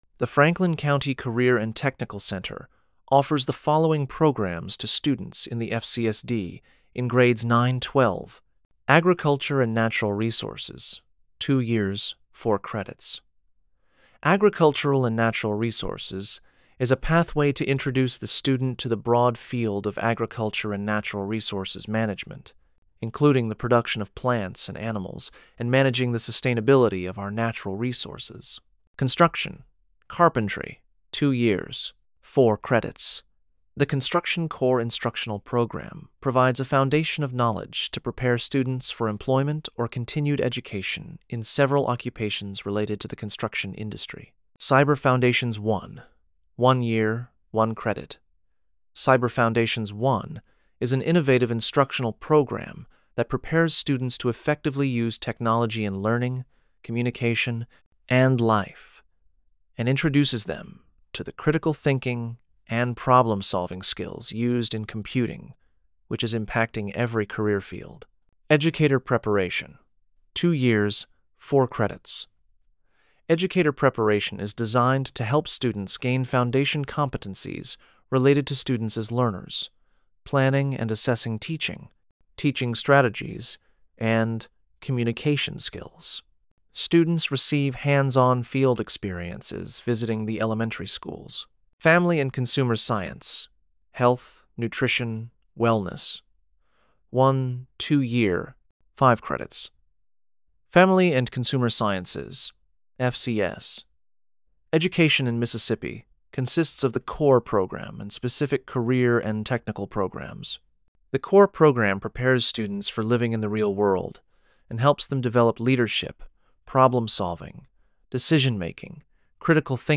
CTE Annual Recruitment (TTS)